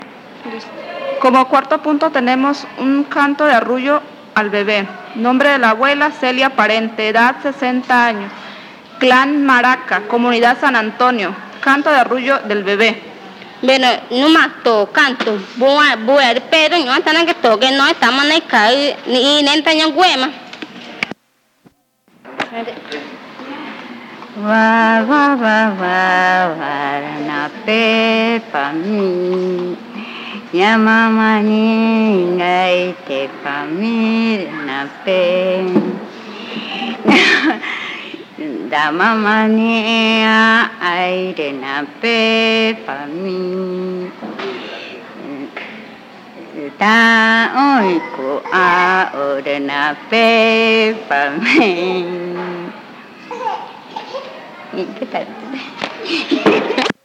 Lullaby 1
Comunidad Indígena Nazareth